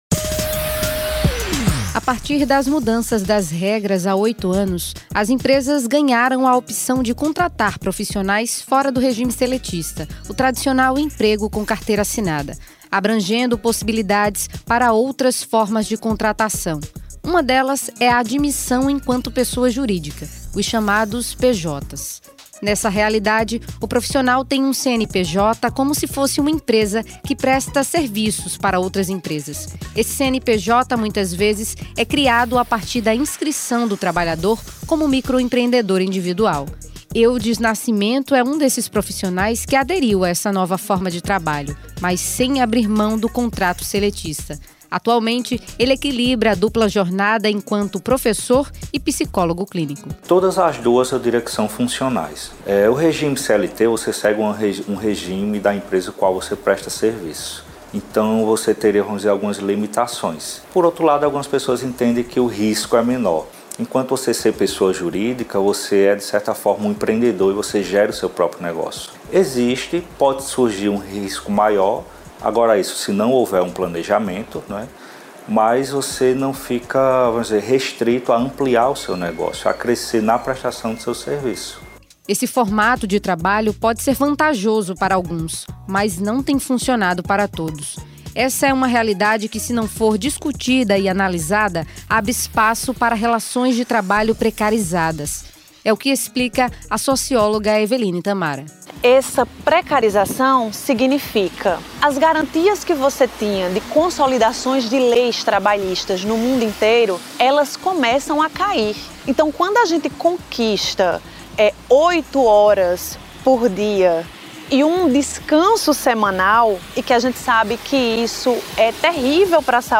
Reportagem especial do Núcleo de Dados da Rede Paraíba mostra os dois lados de um regime de trabalho que tem se tornado cada vez mais comum.